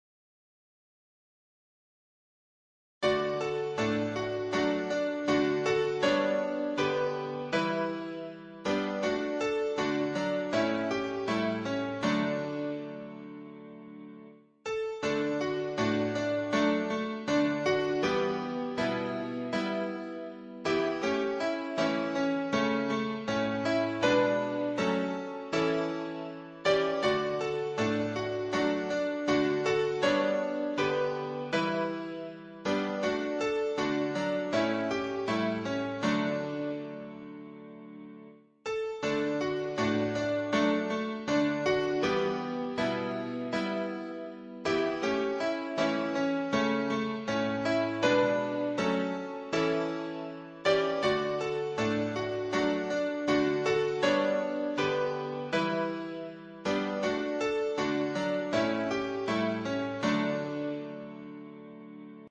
700伴奏
700原唱